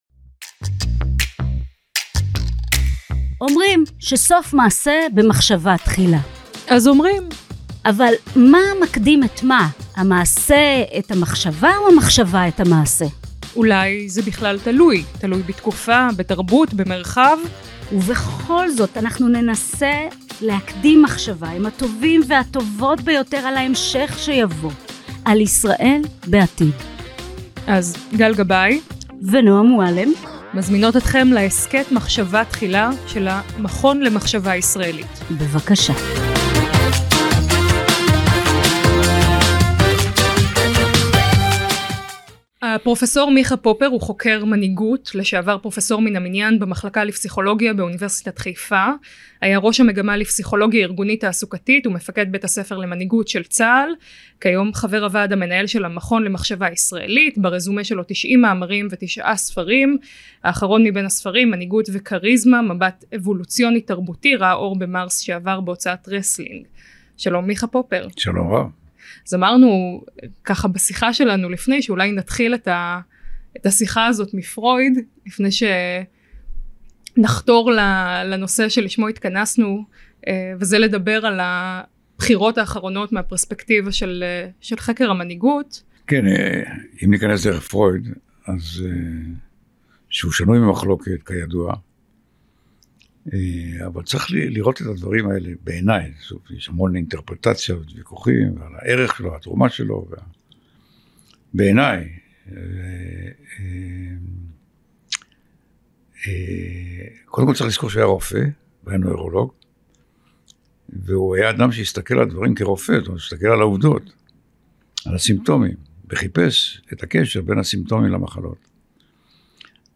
דיונים עם חוקרים העוסקים בבעיות המבניות של ישראל, במטרה ליצור חזון חדש המבוסס על אזוריות, חוקה ולאום ישראלי.